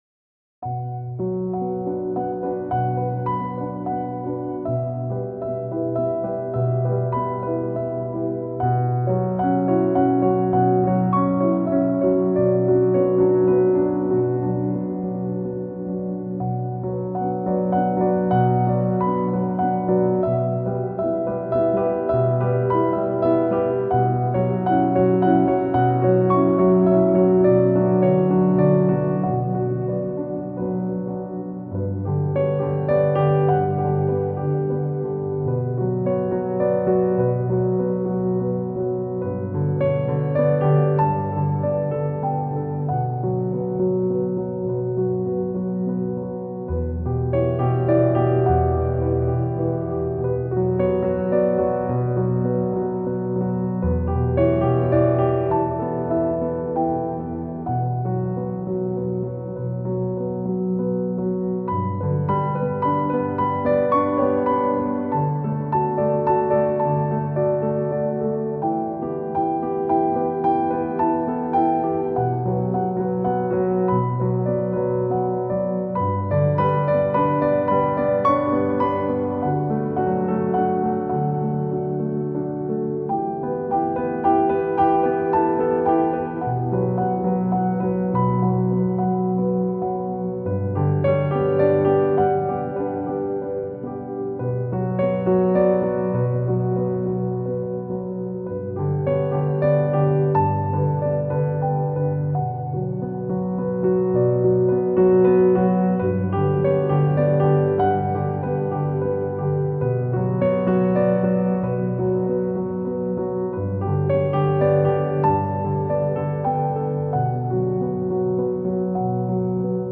سبک آرامش بخش , پیانو , مدرن کلاسیک , موسیقی بی کلام
پیانو آرامبخش